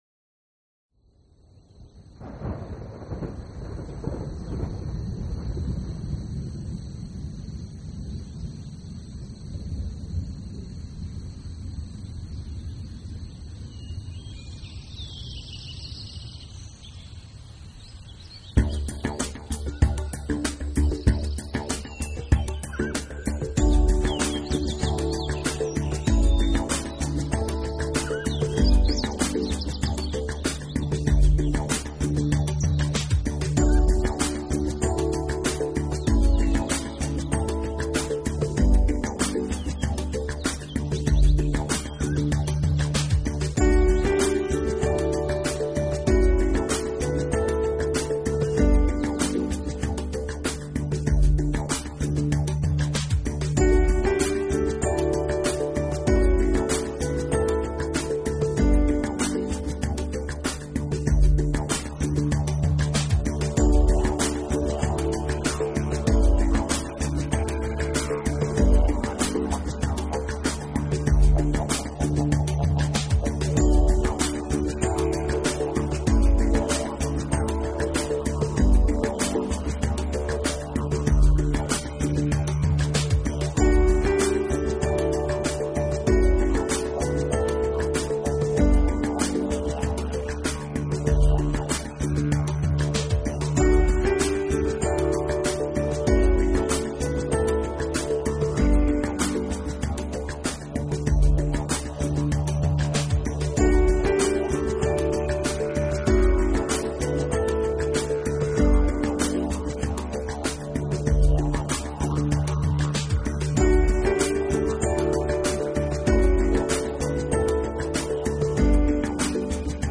类型: New Age
优美的旋律伴着海浪声、